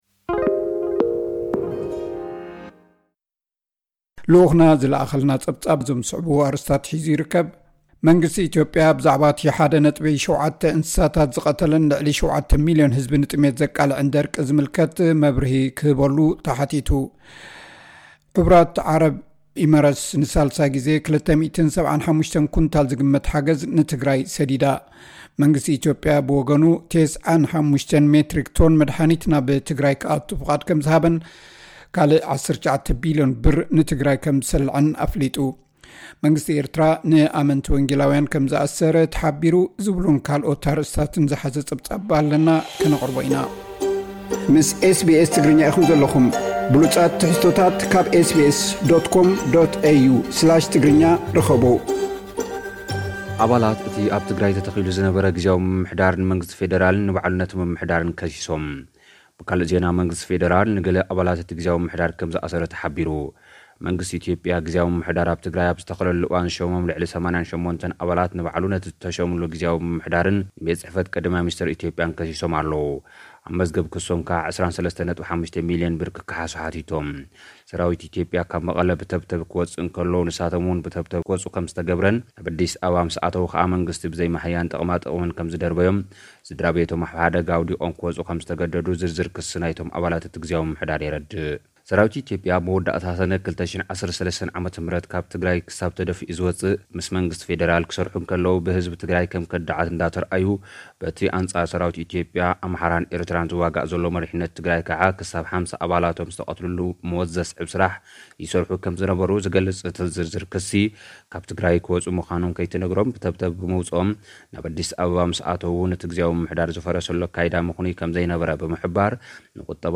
ልኡኽና ዝለኣኸልና ጸብጻብ እዞም ዝስዕቡ ኣርእስታት ሒዙ ኣሎ። ኣባላት እቲ ኣብ ትግራይ ተተኺሉ ዝነበረ ጊዝያዊ ምምሕዳር ንመንግስቲ ፌደራልን ነቲ ምምሕዳርን ከሲሶም። ብካልእ ዜና መንግስቲ ፌደራል ንገለ ኣባላት እቲ ጊዝያዊ ምምሕዳር ከም ዝኣሰረ ተሓቢሩ።